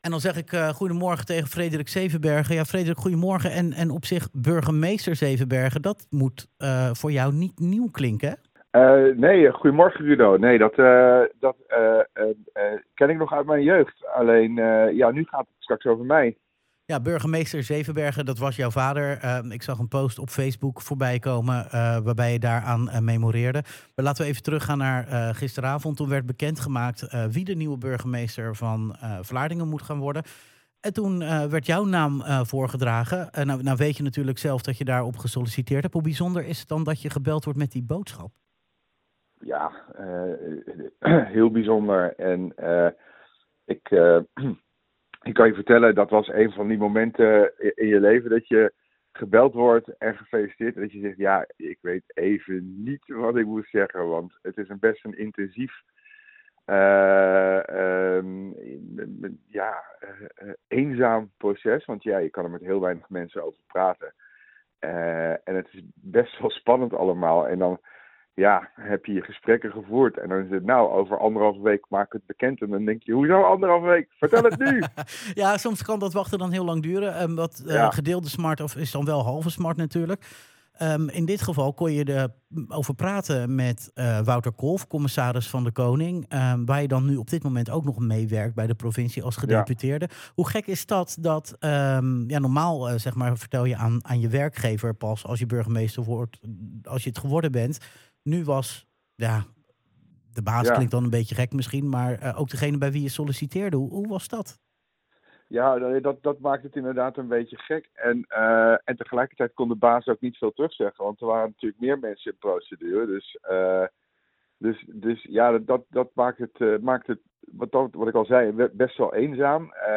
in gesprek met Frederik Zevenbergen over zijn voordracht.